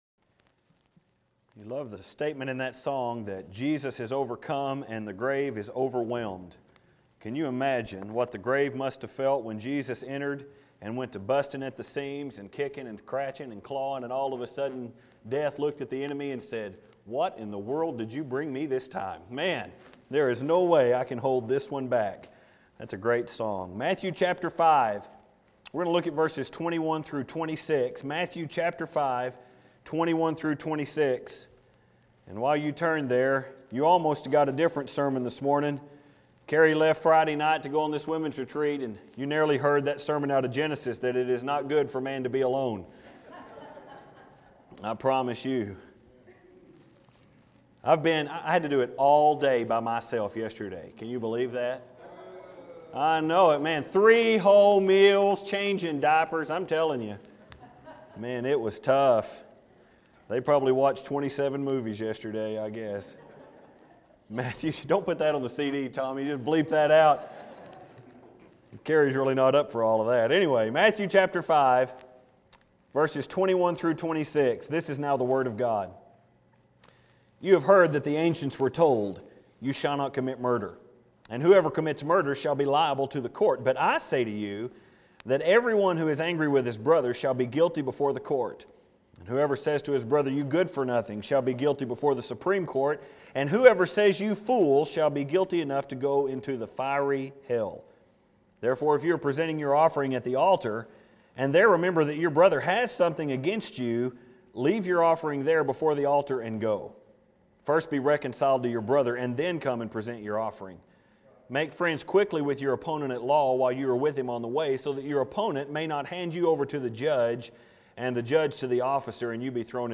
It is a sermon meant to expose faulty righteousness, And by now I think we can all agree that This sermon accomplishes it’s goal. Jesus first revealed to us 8 qualities of a righteous man.